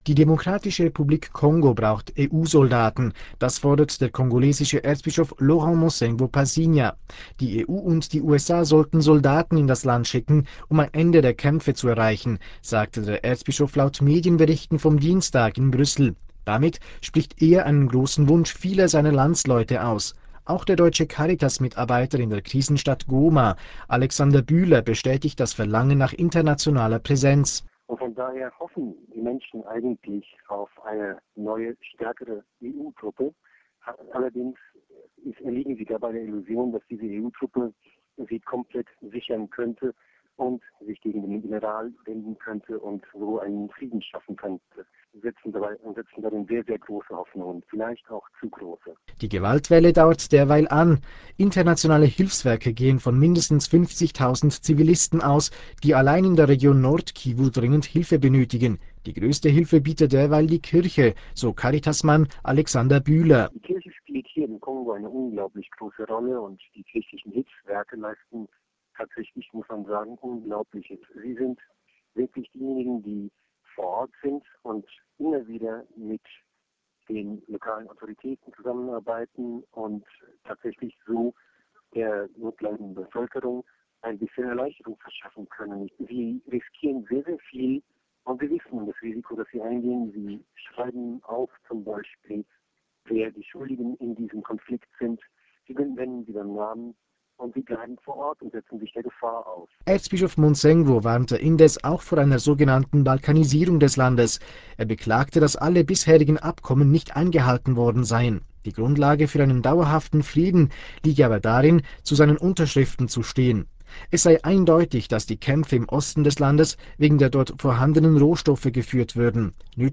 Das Interview in Radio Vatikan / dt. Dienst hier zum Anhören.